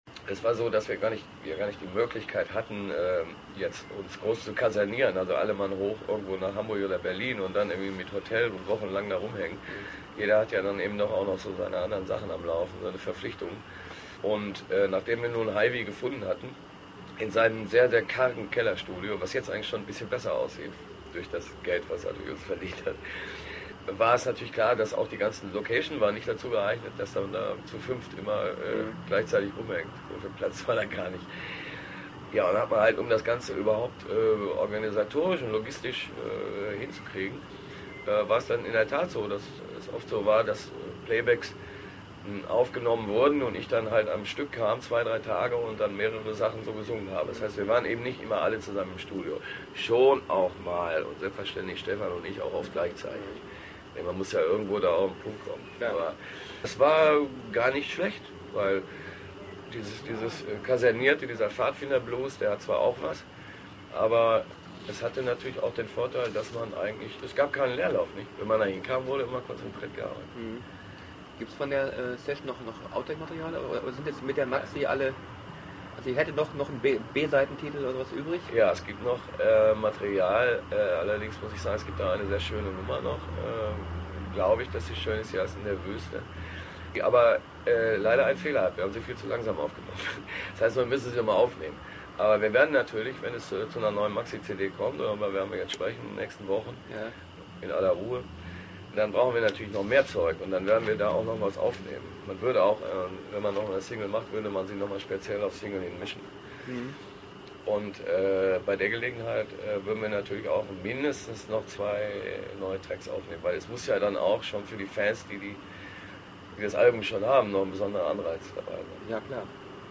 Hier gibt es ein paar akustische Momentaufnahmen zu ausgewählten Themen.